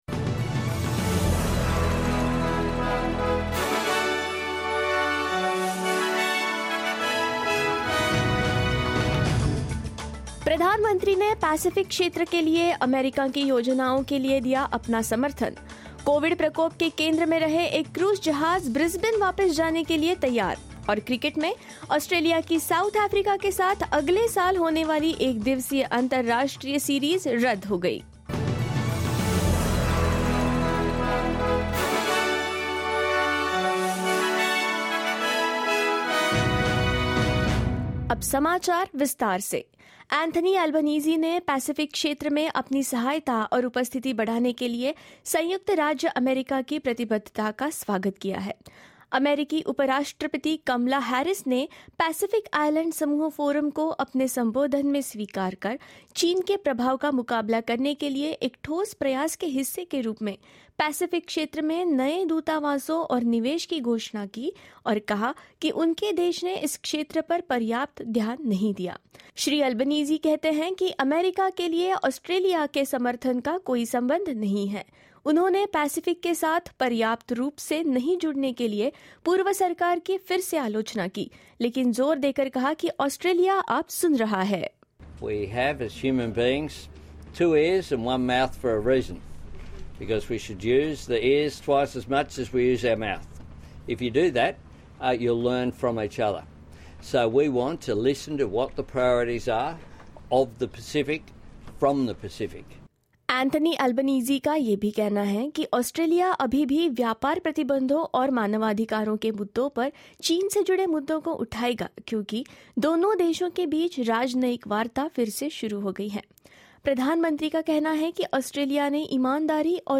news_hindi_1307.mp3